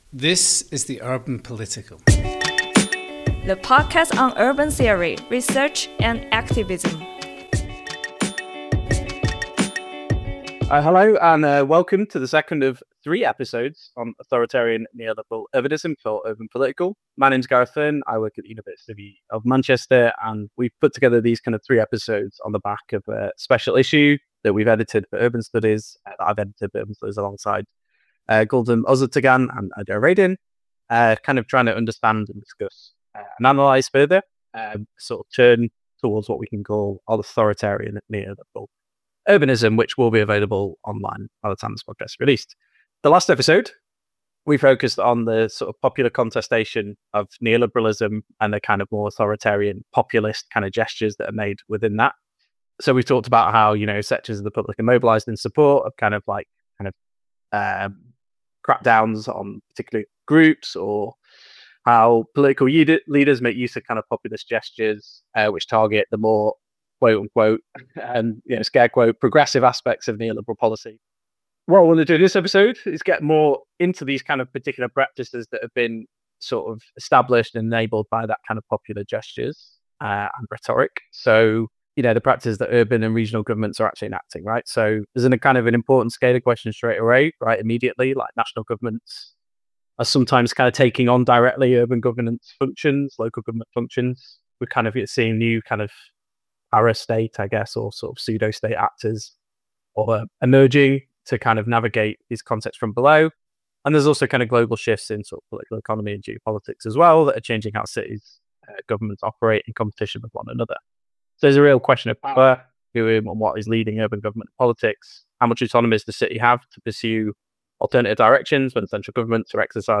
Through conversations with researchers, activists, and practitioners, the series takes stock of this authoritarian conjuncture and asks how power, urbanisation, and resistance intersect in shaping our worlds. This episode focuses on the turn towards an ‘authoritarian populism’ as means of securing and extending neoliberal urban policy, and the extent to which a new political formation is being formed through popular contestation in and over urban space.